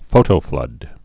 (fōtō-flŭd)